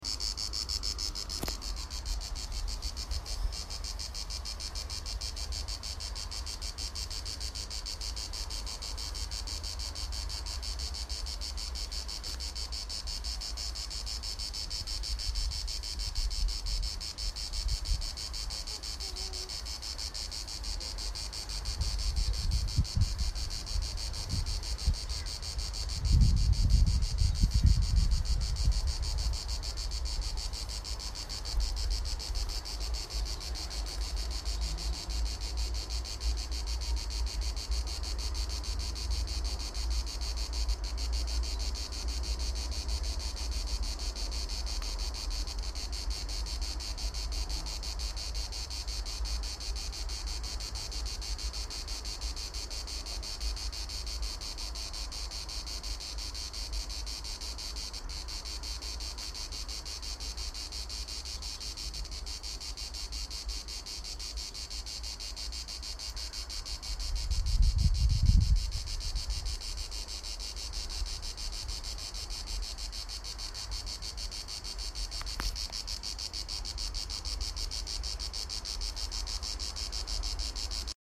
Cicadas - Chia, Sardinia